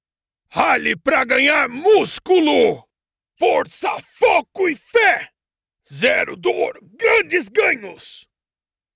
Demonstração Comercial
Animação
Minha configuração de estúdio de última geração garante qualidade de som excepcional para cada projeto.
Cabine acústica perfeita
Mics sE Eletronics T2
Jovem adulto
Meia-idade
BarítonoGravesBaixoMuito baixo